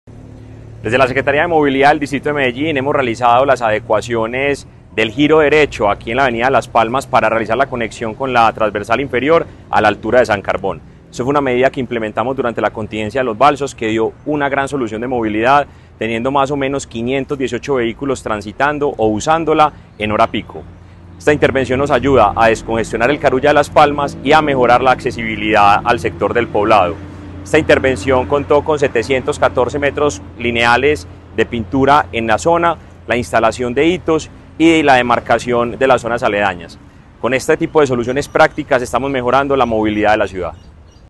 Declaraciones del secretario de Movilidad, Mateo González.
Declaraciones-del-secretario-de-Movilidad-Mateo-Gonzalez.-Glorieta-virtual-Palmas.mp3